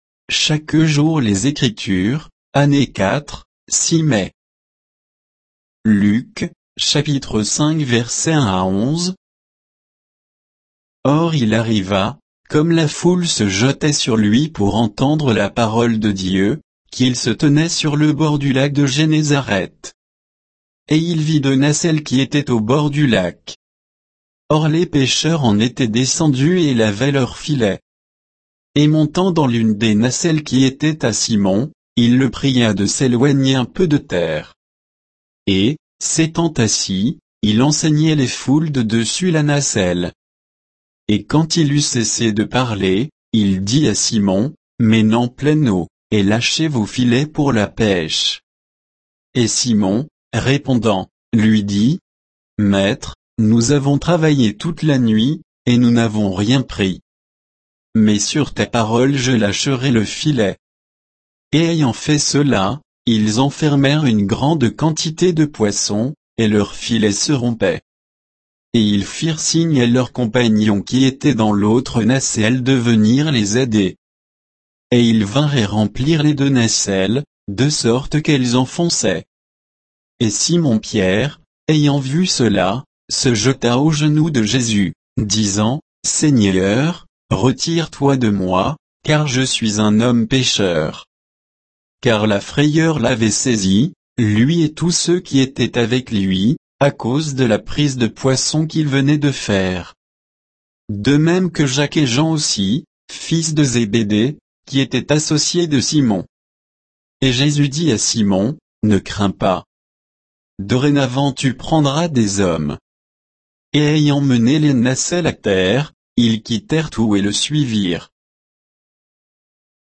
Méditation quoditienne de Chaque jour les Écritures sur Luc 5